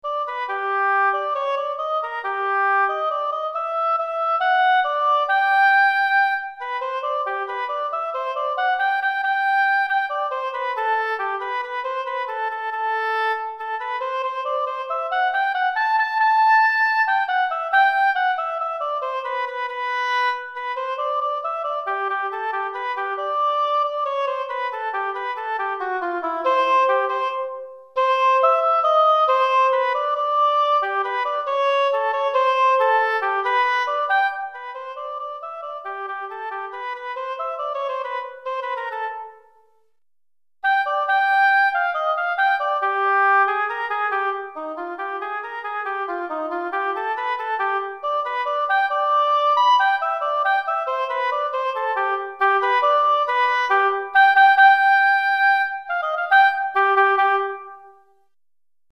Etude pour Hautbois - Hautbois Solo